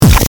death.mp3